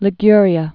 (lĭ-gyrē-ə)